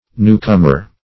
Newcomer \New"com`er\, n.